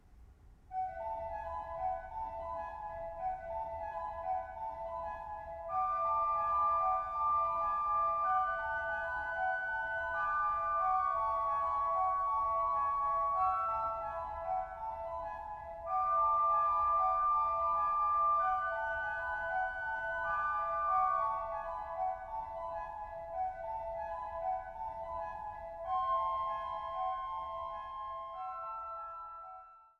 Stellwagen-Orgel